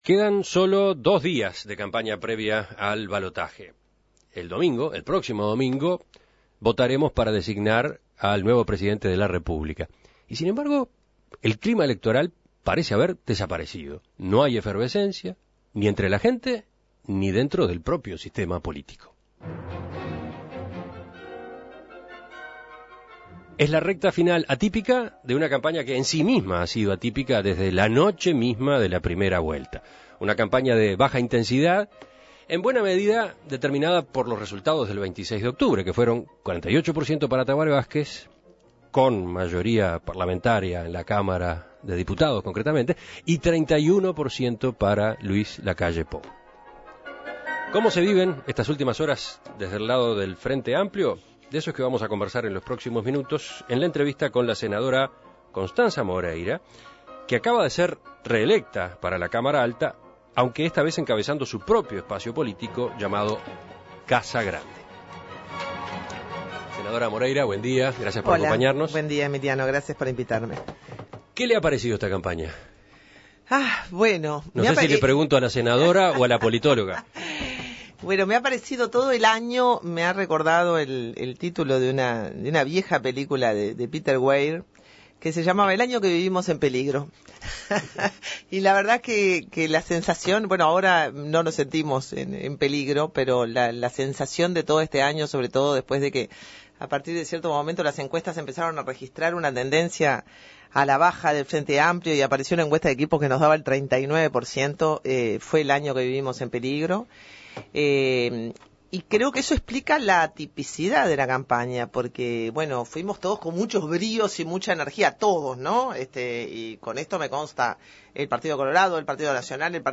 Escuche la entrevista En Perspectiva